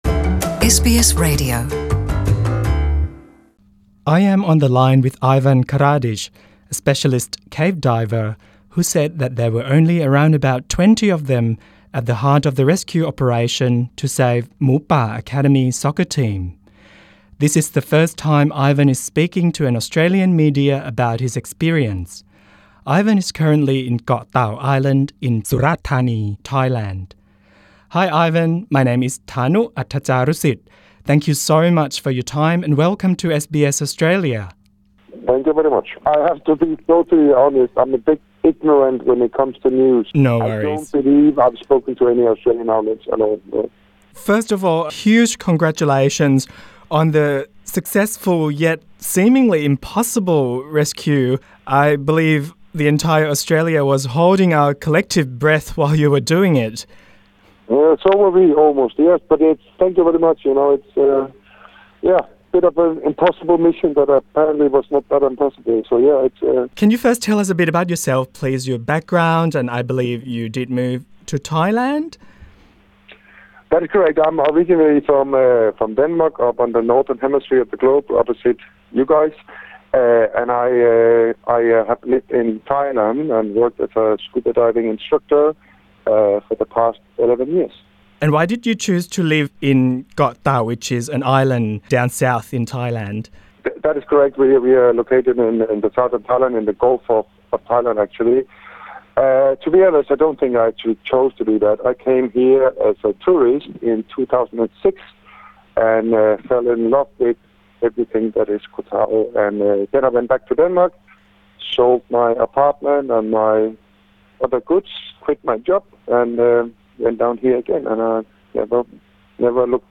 Download the SBS Audio app Available on iOS and Android EXCLUSIVE: Australia’s first interview